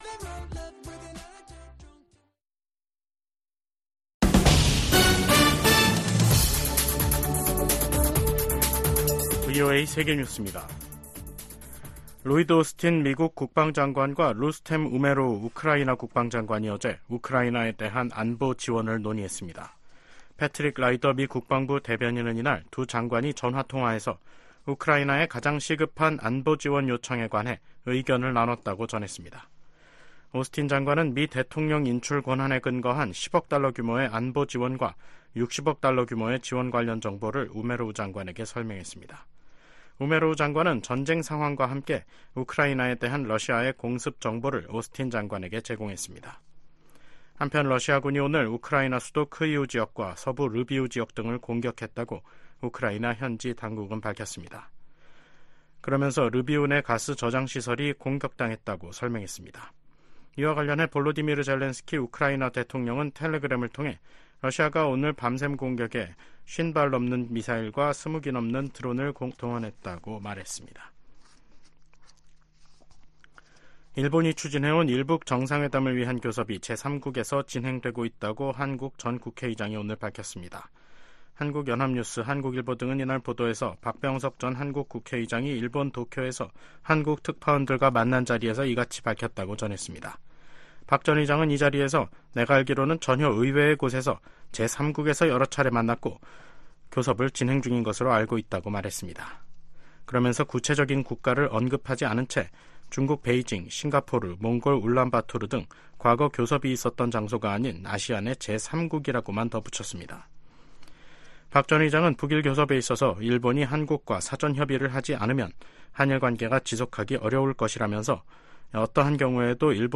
VOA 한국어 간판 뉴스 프로그램 '뉴스 투데이', 2024년 5월 8일 3부 방송입니다. 도널드 트럼프 전 대통령은 자신이 대통령에 당선되면 한국이 주한미군 주둔 비용을 더 많이 부담하지 않을 경우 주한미군을 철수할 수 있음을 시사했습니다. 러시아 회사가 수천 톤에 달하는 유류를 북한으로 운송할 유조선을 찾는다는 공고문을 냈습니다.